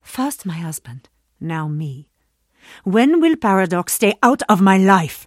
Lady Geist voice line - First my husband.